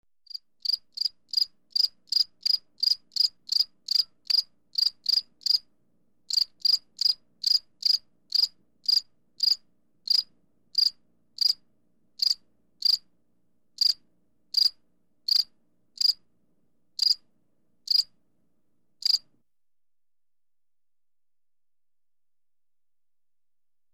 Звуки кузнечиков
Звук единственного сверчка